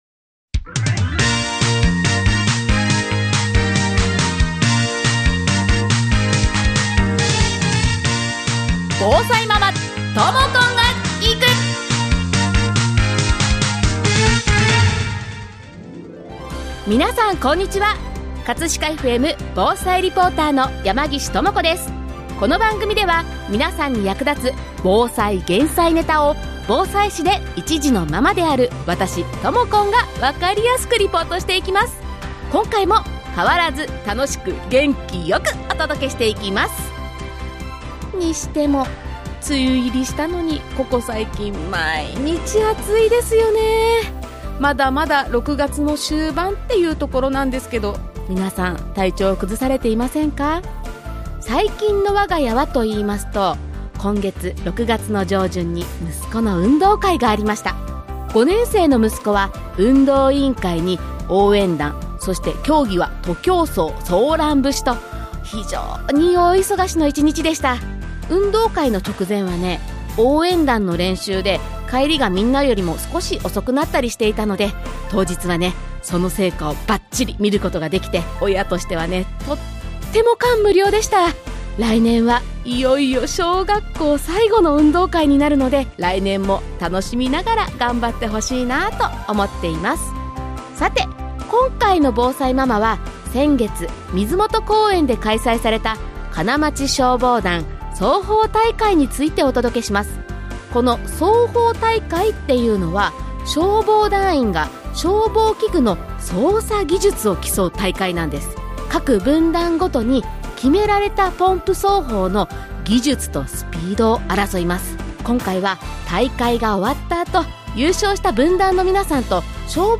操法大会が終了した後は優勝した3分団の皆さん、そして団本部の方にお話を伺いました。 そして今回はスタジオに私の仲間！女性隊の皆さんをお呼びして操法大会の感想などを伺いました。